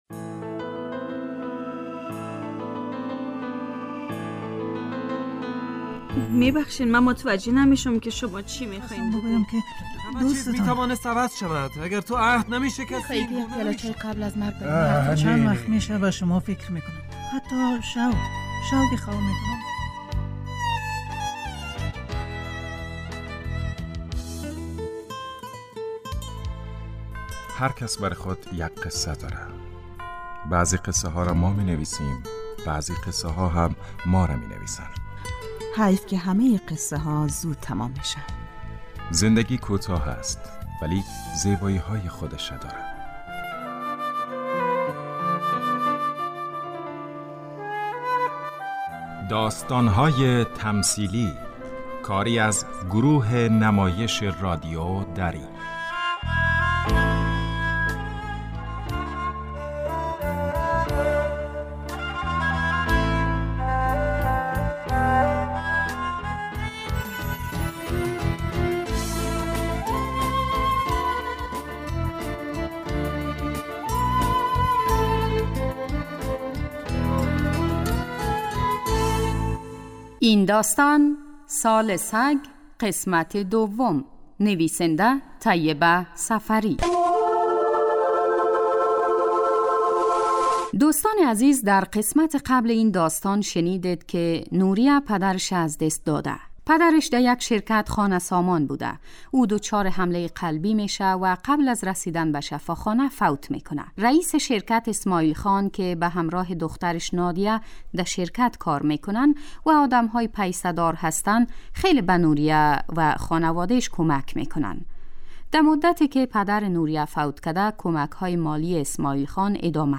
داستان تمثیلی / سال سگ